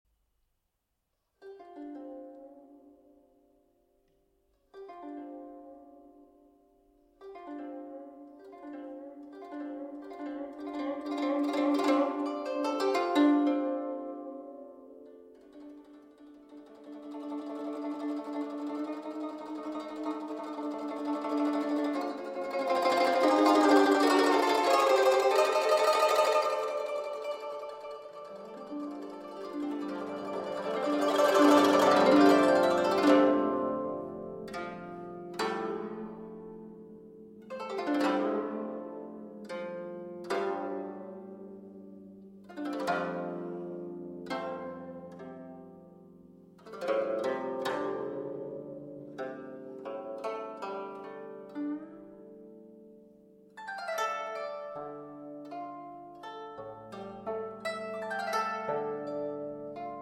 Baroque Ensemble